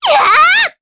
Mediana-Vox_Attack4_jp.wav